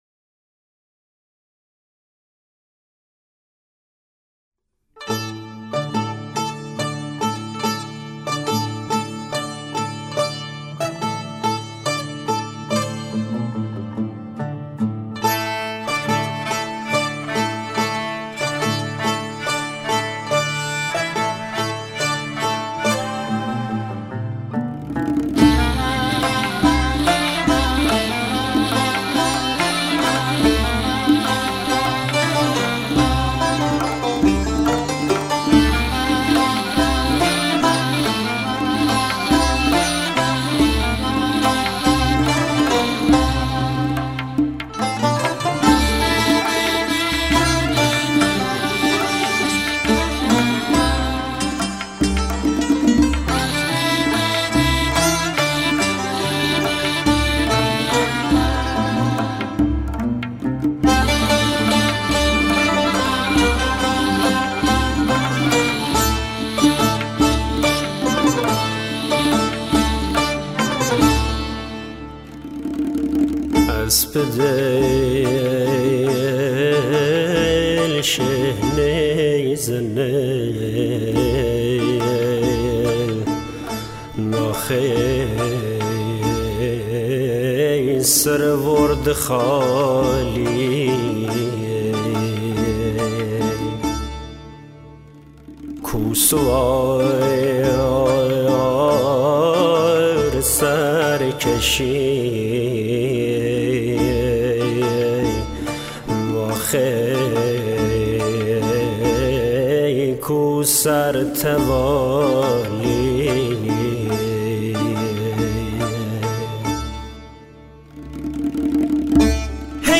آهنگ شیرازی